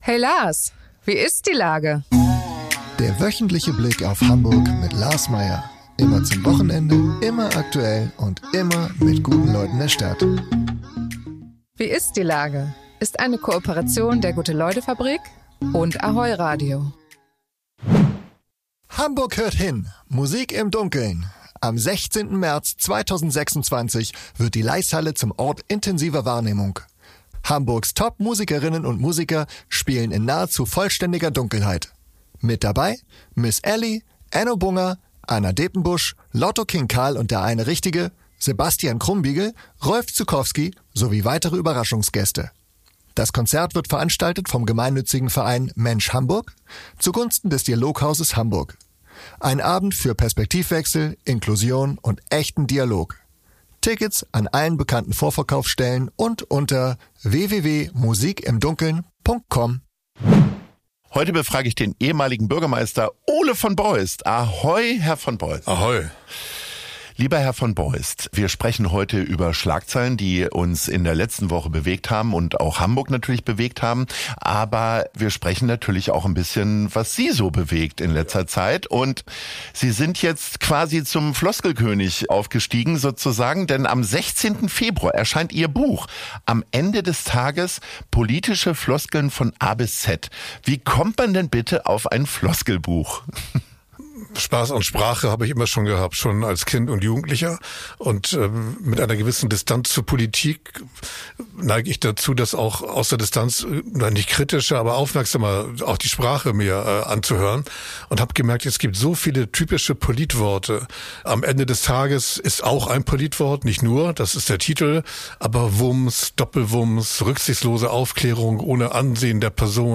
In der aktuellen Folge von „Wie ist die Lage?“ ist Hamburgs ehemaliger Bürgermeister Ole von Beust zu Gast.